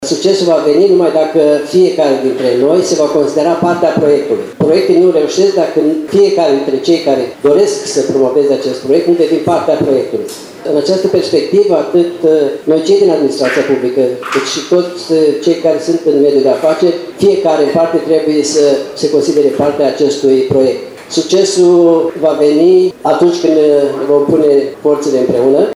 El a declarat – la lansarea revistei Pentru Localnici, de la Camera de Comerț și Industrie Suceava – că dezvoltarea unor proiecte bilaterale “reprezintă varianta cea mai eficientă pentru creșterea eficienței economice”.